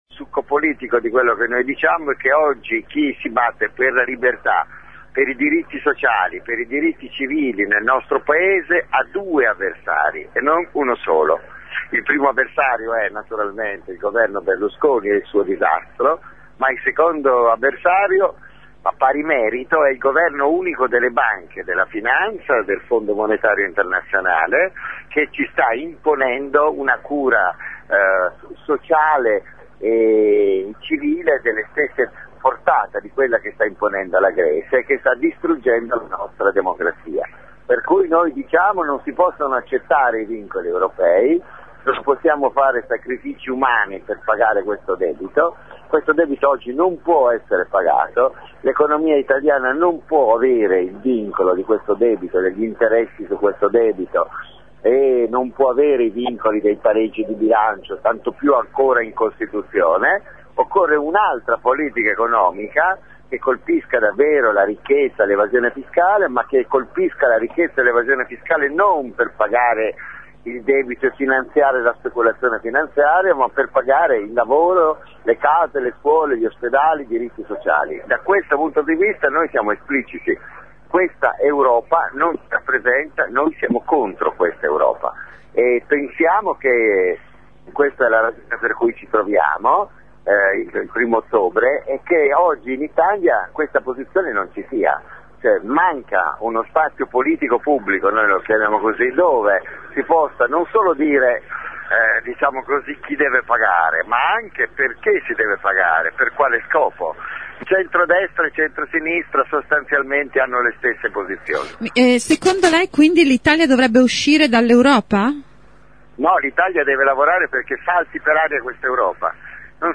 Lo abbiamo intervistato: